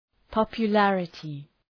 {,pɒpjə’lærətı}